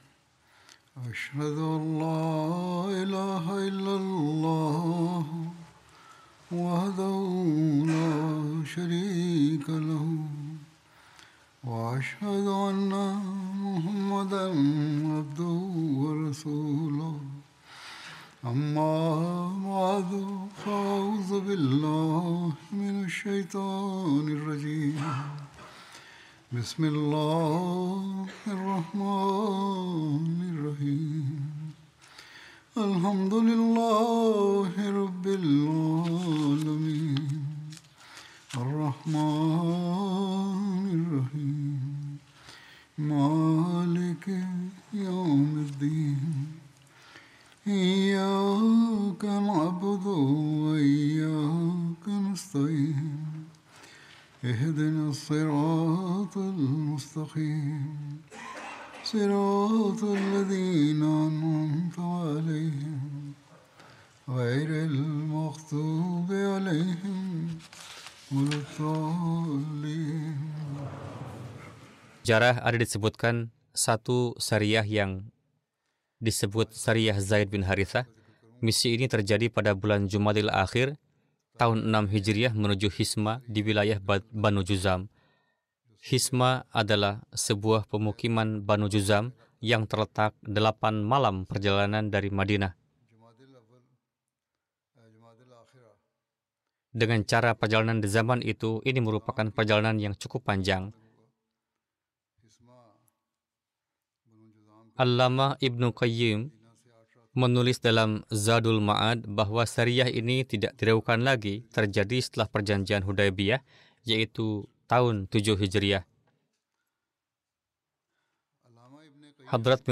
Indonesian Translation of Friday Sermon delivered by Khalifatul Masih